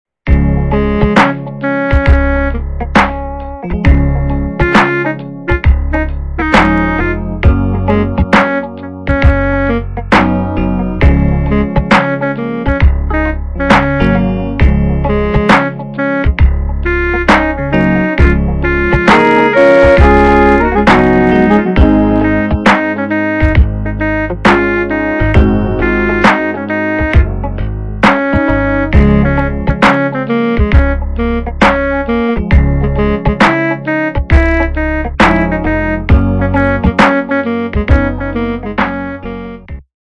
- западная эстрада
полифоническую мелодию